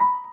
piano_last19.ogg